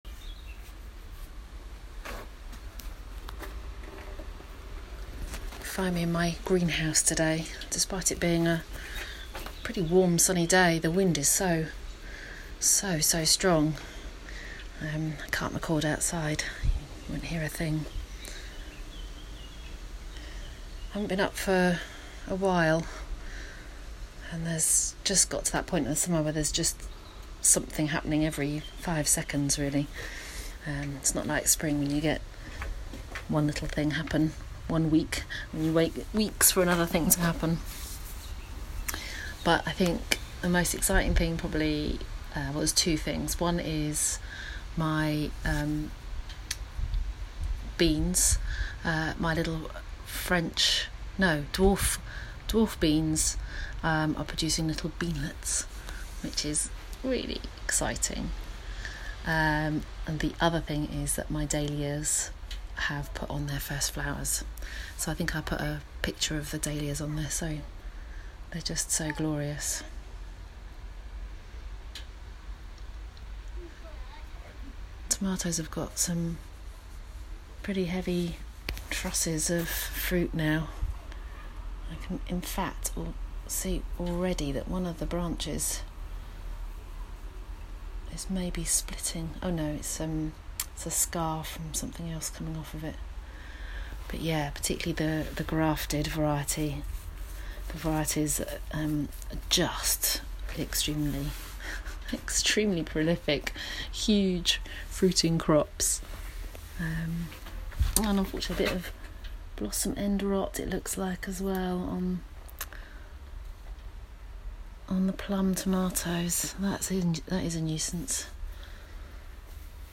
Hear from one woman and her dog as she explores the organic gardening world in short sound bites.
*Please note, outside recording carried out in accordance with national guidance as part of daily exercise with respect to social distancing*